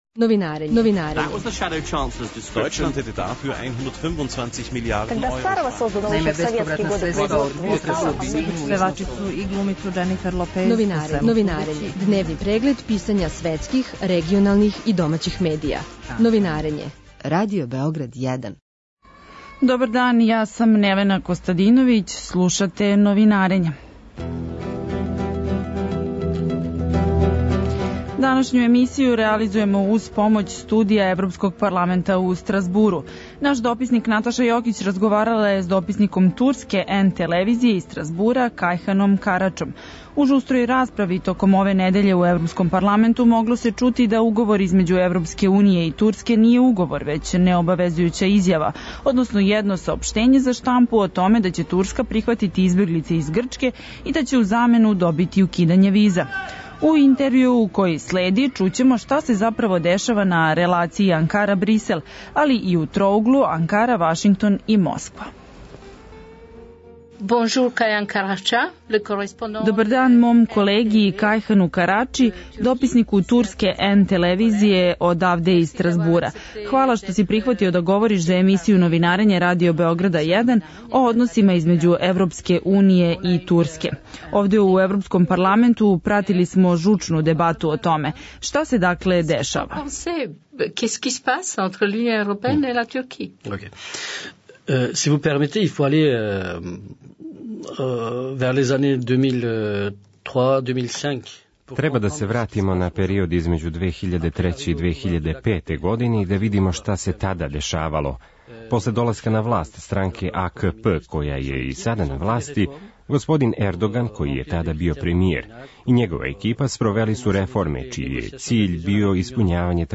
Данашњу емисију реализујемо уз помоћ студија Европског парламента у Стразбуру.